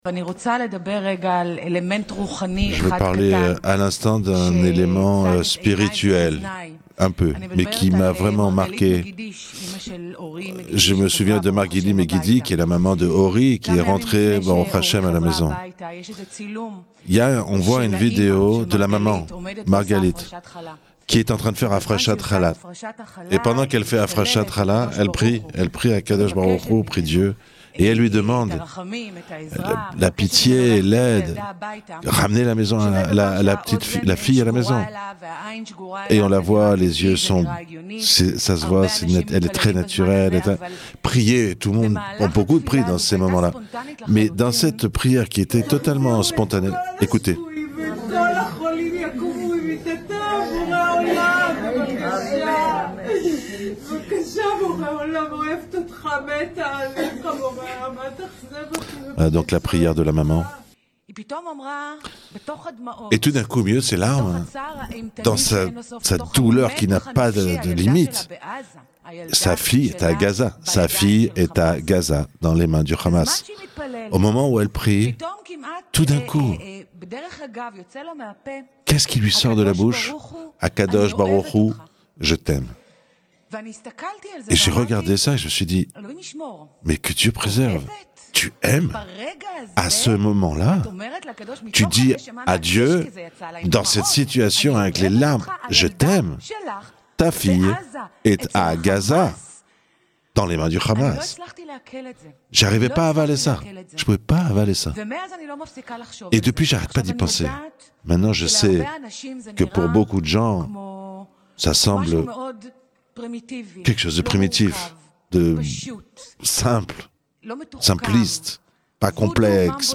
Discours magnifique de Galit Atbaryan à la Knesset